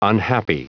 Prononciation du mot unhappy en anglais (fichier audio)
Prononciation du mot : unhappy